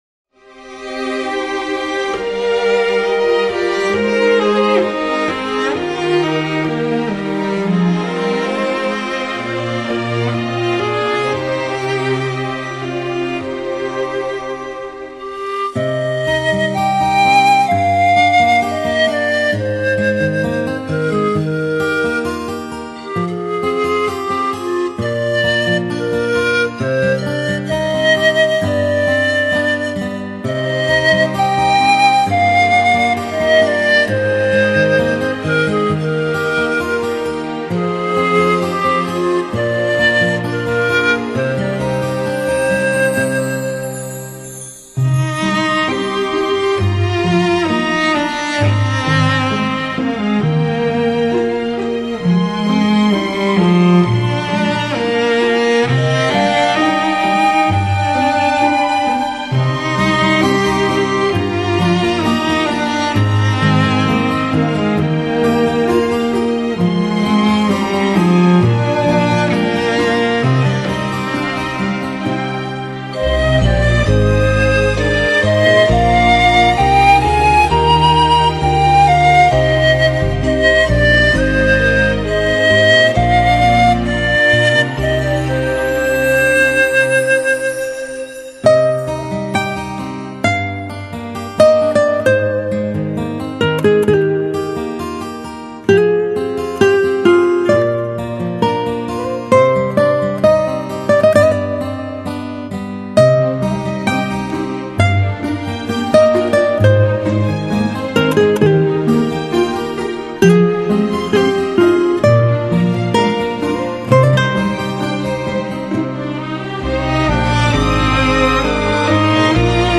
[5/5/2010]纯音 陶笛一曲 激动社区，陪你一起慢慢变老！